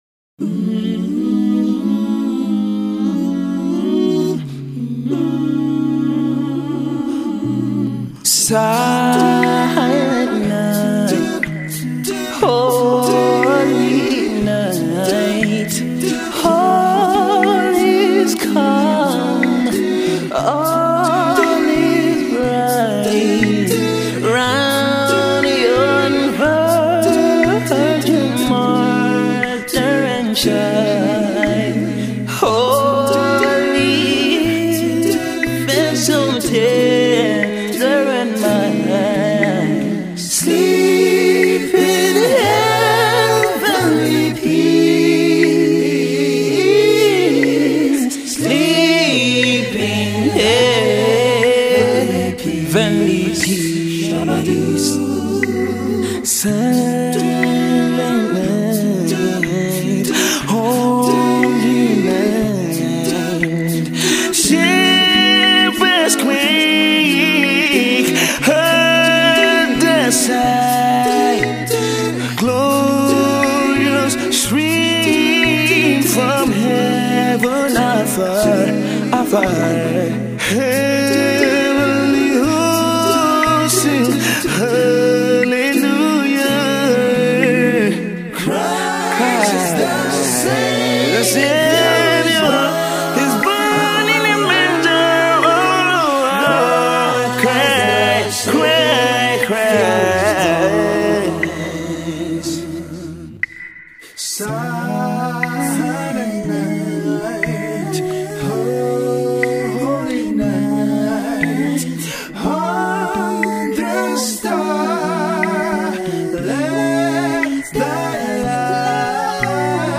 a Nigerian gospel band
Christmas carol
very well delivered acapella rendition